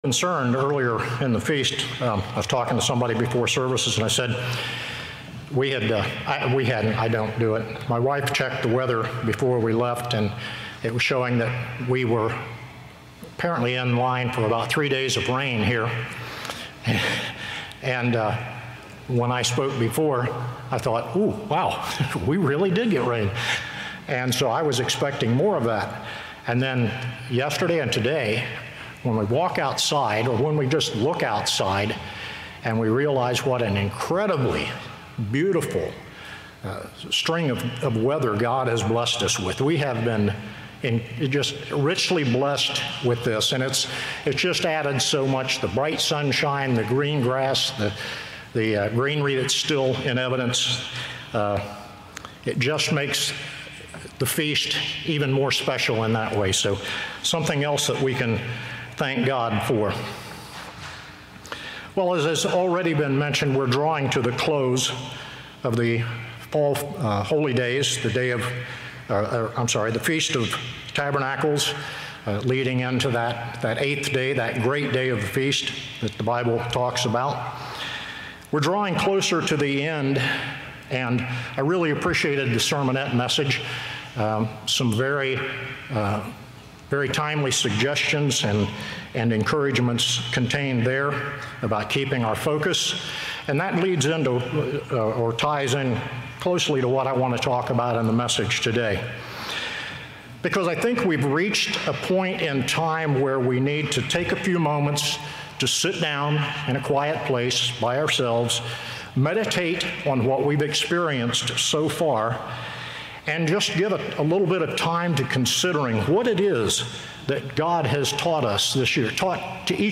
This sermon was given at the Lancaster, Pennsylvania 2021 Feast site.